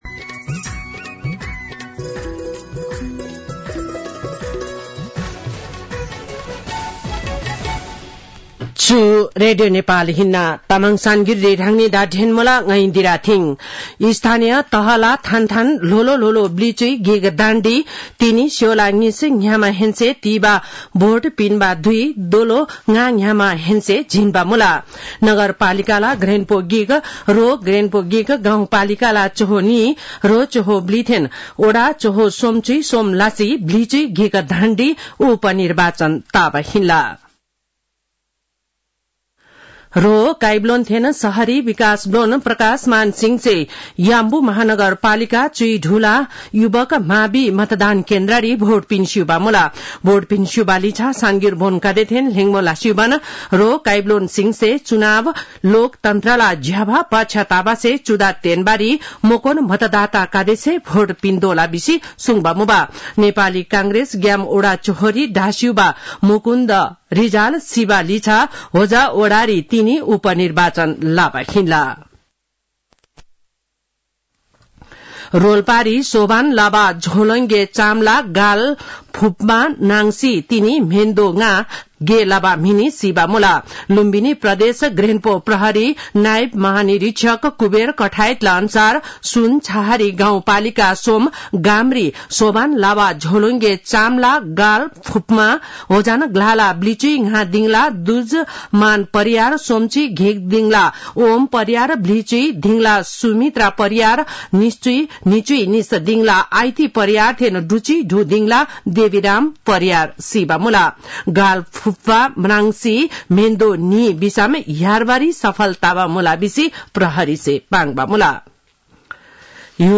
तामाङ भाषाको समाचार : १७ मंसिर , २०८१